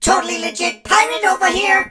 darryl_start_vo_06.ogg